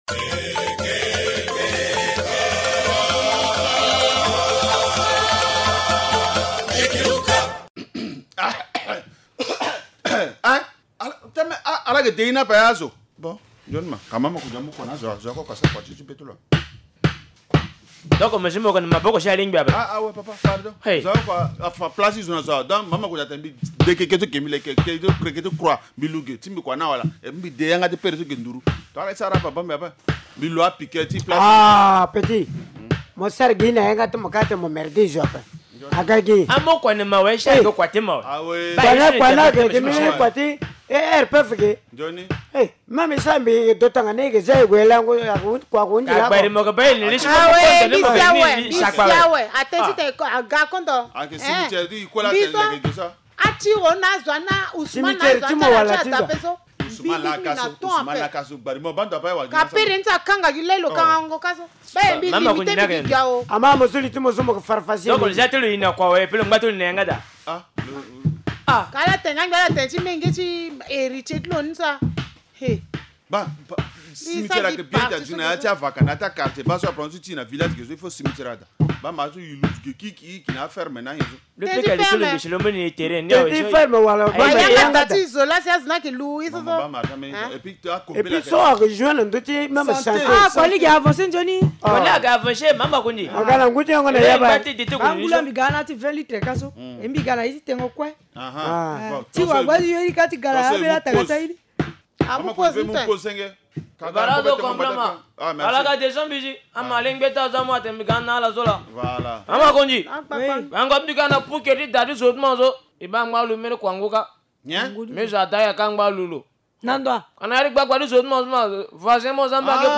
Linga théâtre : l’inhumation des corps dans les parcelles irrite les sages de Linga